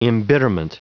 Prononciation du mot embitterment en anglais (fichier audio)
Prononciation du mot : embitterment